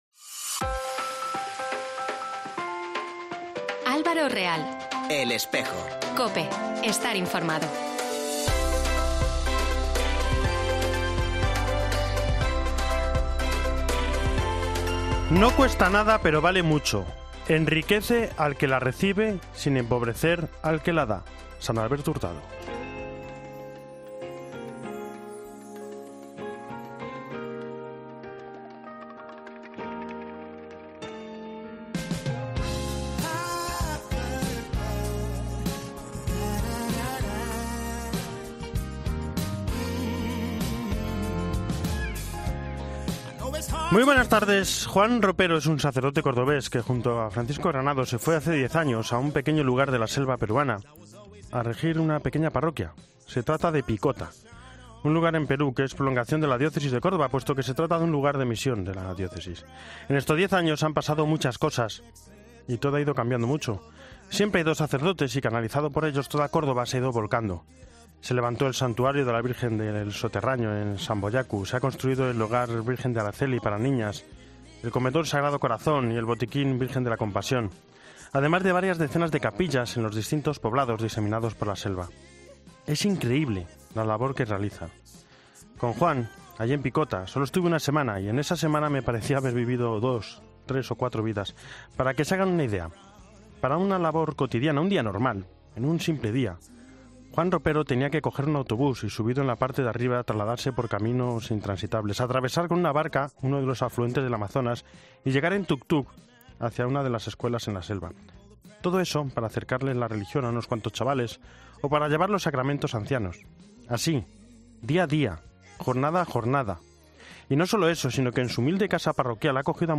AUDIO: Hoy Evangelio, Roma, actualidad eclesial, reportaje